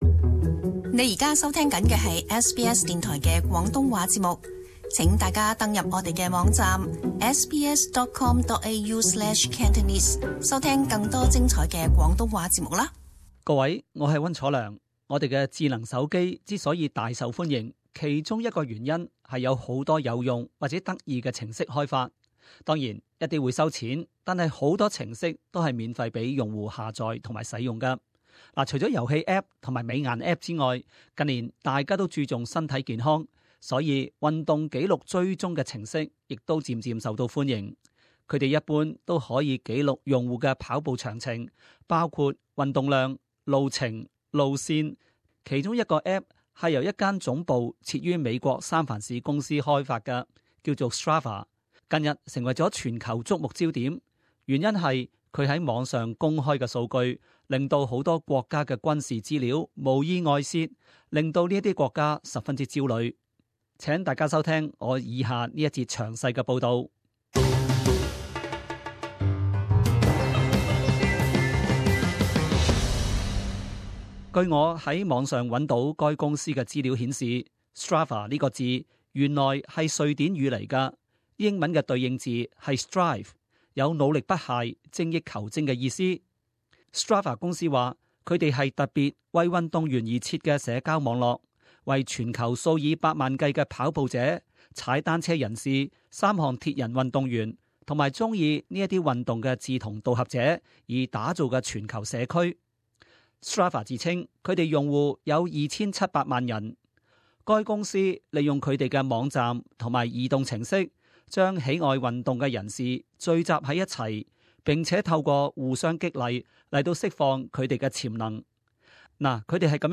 SBS广东话播客